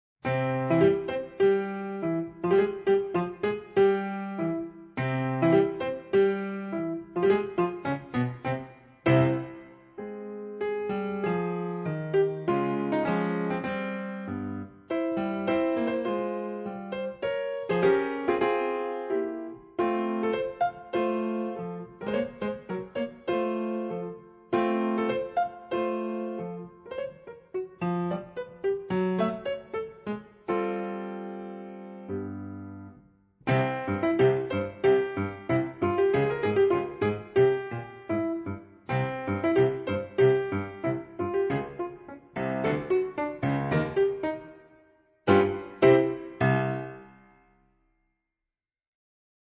Per pianoforte con CD allegato